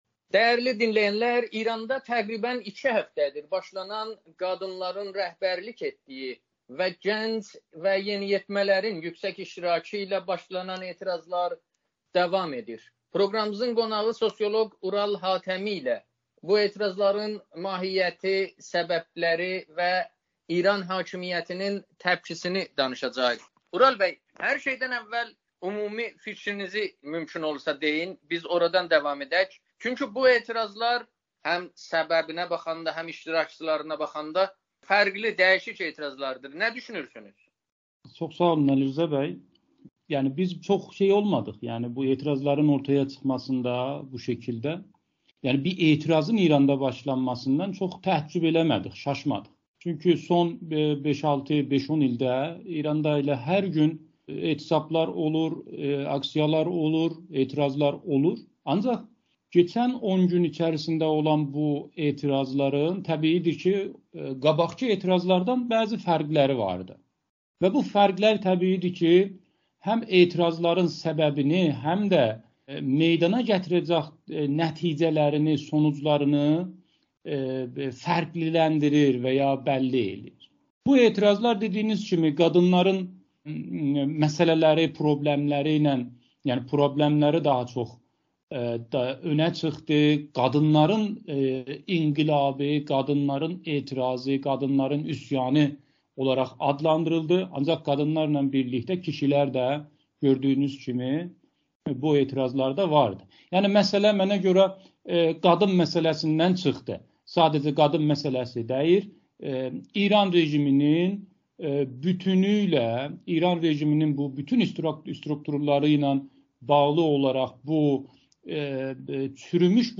müsahibədə